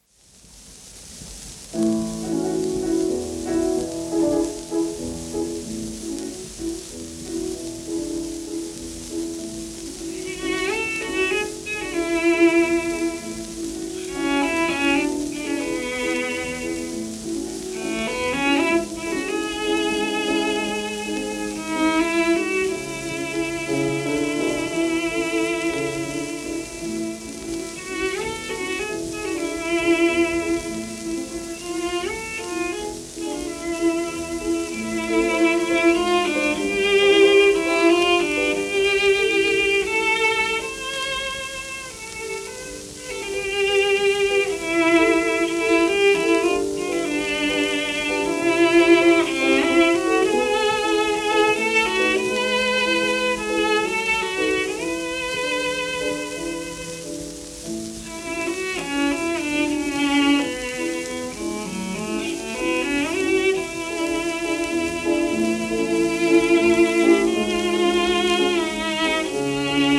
フェリックス・サルモンド(Vc:1888-1952)
w/ピアノ
1927年録音
イギリス出身のサルモンドはチェロ奏者で教師としてアメリカでも活動した。